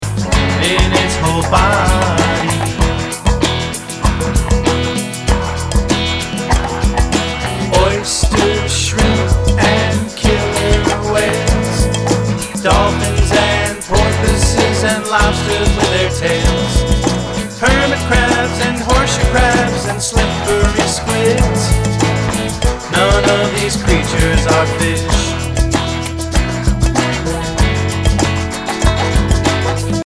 Tuneful and bubbly